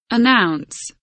announce kelimesinin anlamı, resimli anlatımı ve sesli okunuşu